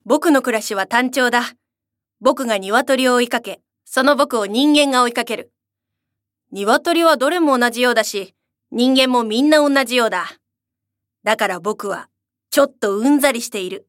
ボイスサンプル
セリフB